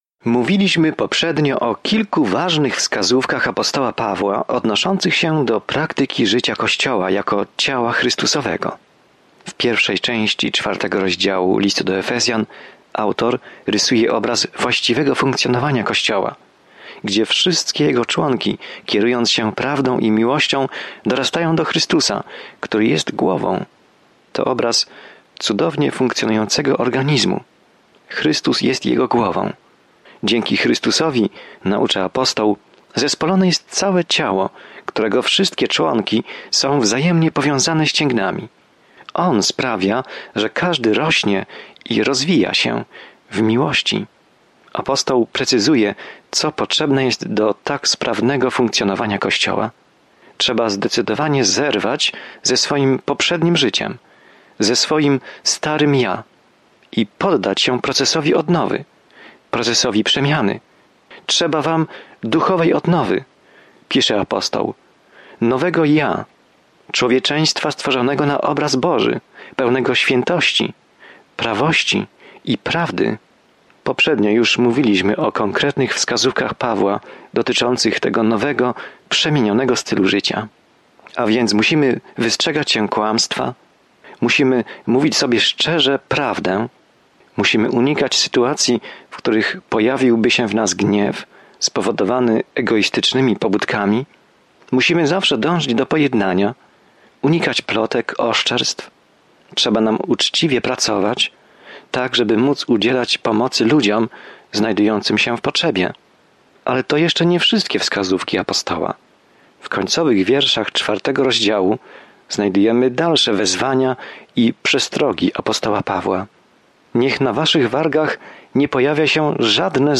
Pismo Święte Efezjan 4:30-32 Efezjan 5:1-4 Dzień 17 Rozpocznij ten plan Dzień 19 O tym planie List do Efezjan wyjaśnia, jak żyć w Bożej łasce, pokoju i miłości, ukazując piękne wyżyny tego, czego Bóg pragnie dla swoich dzieci. Codziennie podróżuj przez Efezjan, słuchając studium audio i czytając wybrane wersety słowa Bożego.